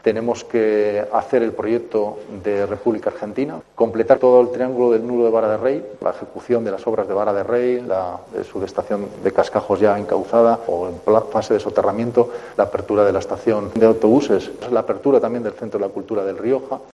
Pablo Hermoso de Mendoza, alcalde de Logroño